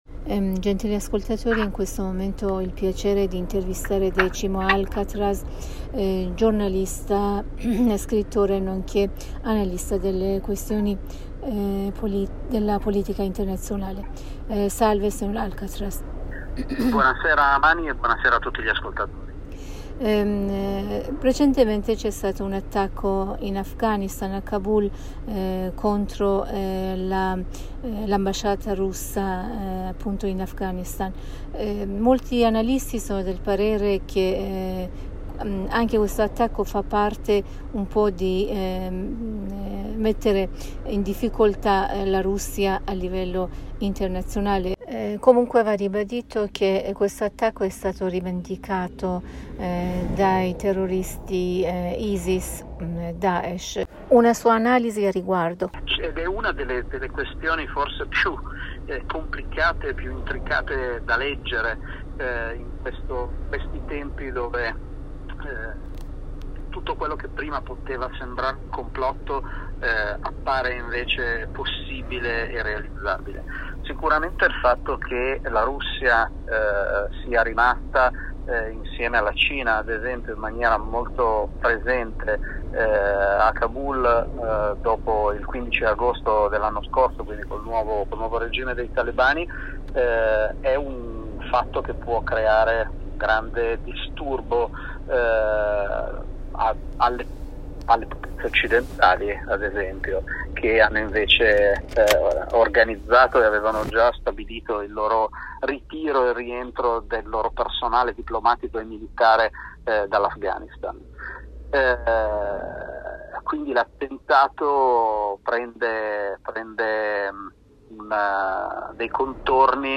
giornalista e scrittore nonche' analista degli questioni della politica internazionale in un collegamento telefonico con la Radio Italia della Voce della Repubblica islamica dell'Iran (IRIB) con il sito http